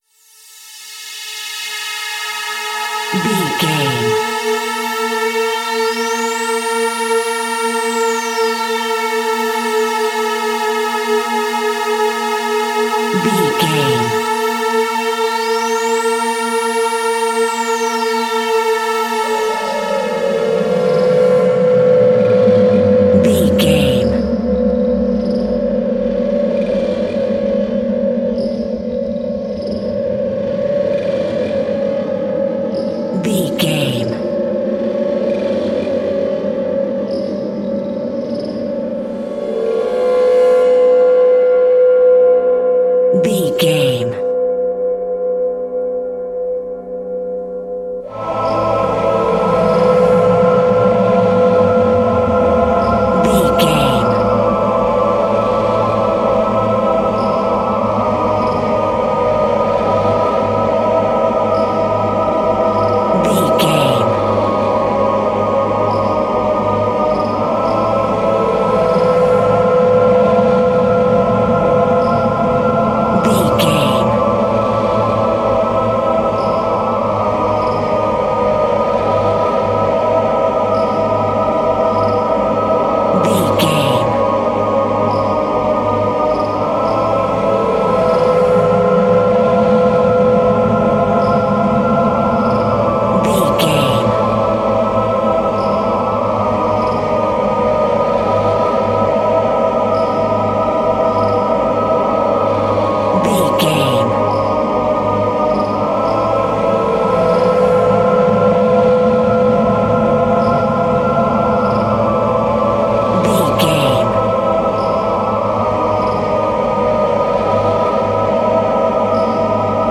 Atonal
Slow
ominous
eerie
synthesiser
spooky
horror music
Horror Pads
horror piano
Horror Synths